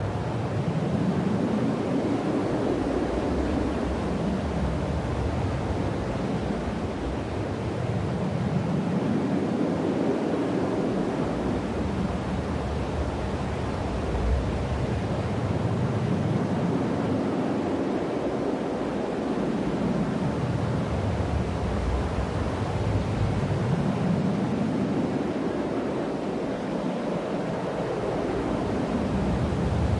合成风 " mr noiser01
描述：用"MR_Noiser01"合奏为Native Instruments Reaktor创作的合成风。
标签： 风能 Reaktor的 嘶嘶 氛围 合成器 微风 环境 氛围 噪声 气氛 合成
声道立体声